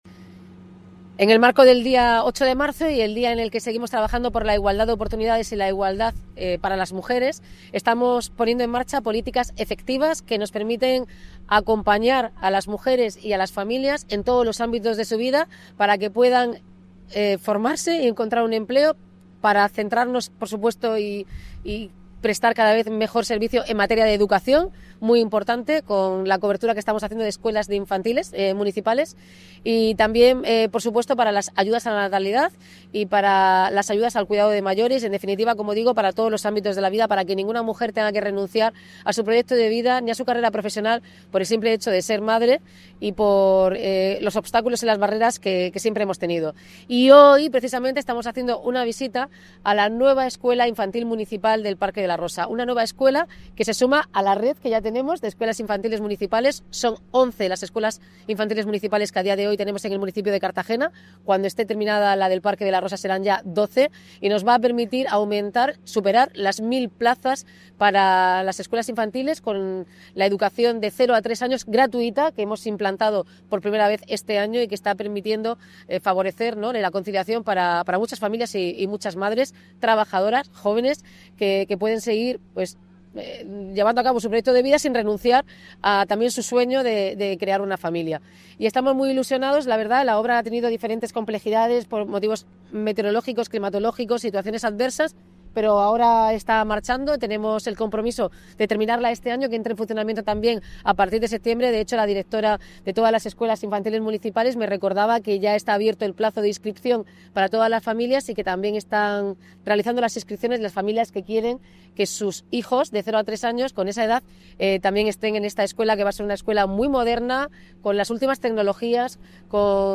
Declaraciones de la alcaldesa, Noelia Arroyo, sobre obras guardería Parque de la Rosa